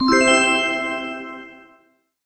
magic_harp_3.ogg